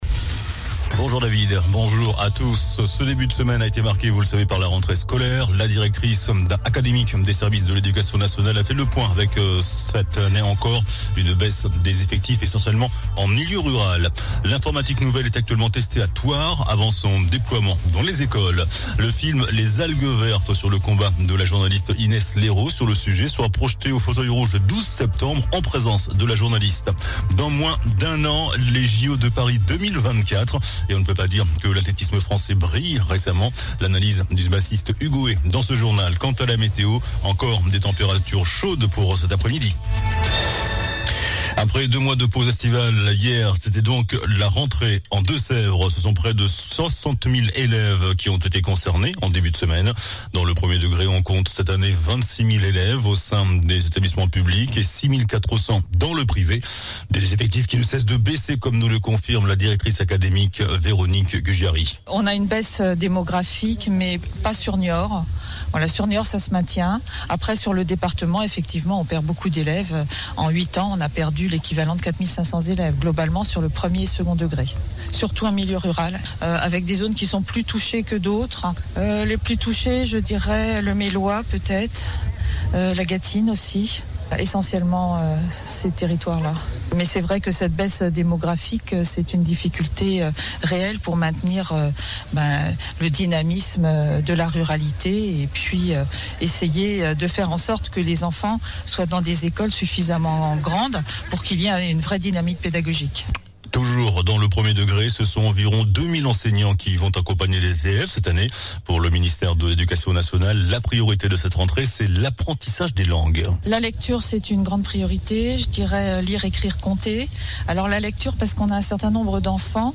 JOURNAL DU MARDI 05 SEPTEMBRE ( MIDI )